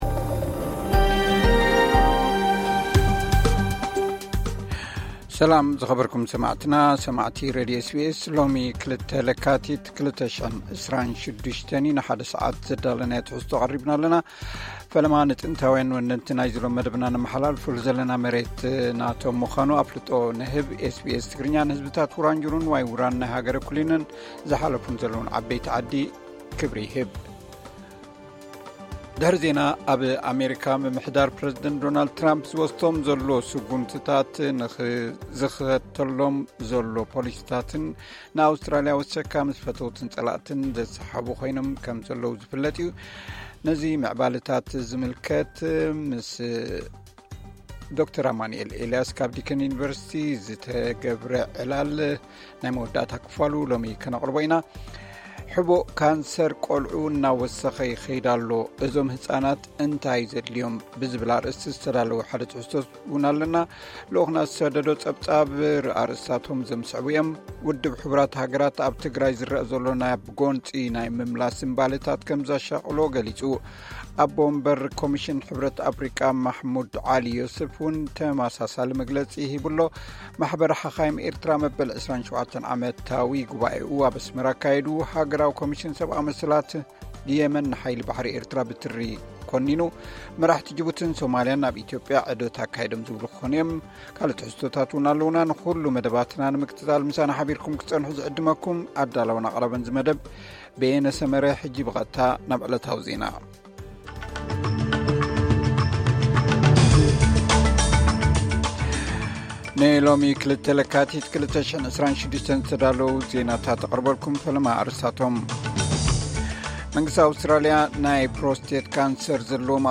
ዕለታዊ ዜና ኤስ ቢ ኤስ ትግርኛ (02 ለካቲ 2026)